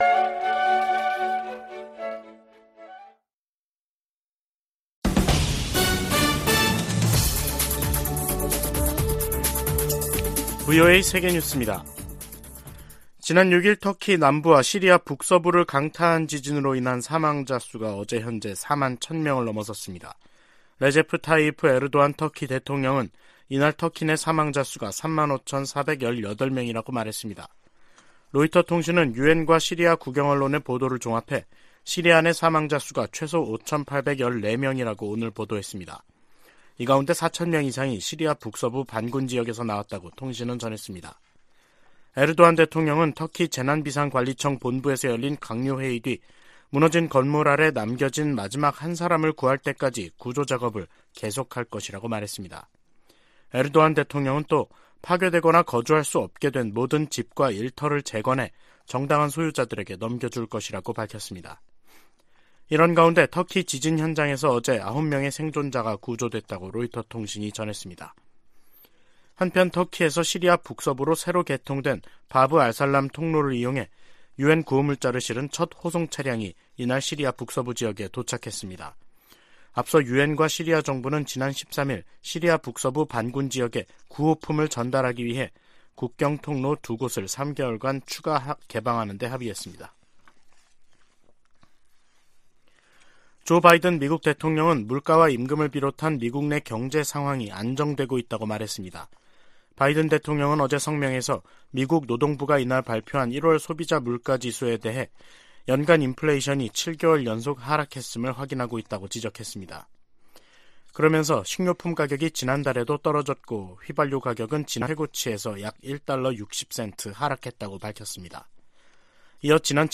VOA 한국어 간판 뉴스 프로그램 '뉴스 투데이', 2023년 2월 15일 2부 방송입니다. 미 국무부가 중국의 정찰풍선 문제를 거론하며, 중국을 미한일 3국의 역내 구상을 위협하는 대상으로 규정했습니다. 백악관은 중국의 정찰풍선 프로그램이 정부의 의도와 지원 아래 운용됐다고 지적했습니다. 북한은 고체연료 ICBM 부대를 창설하는 등, 핵무력 중심 군 편제 개편 움직임을 보이고 있습니다.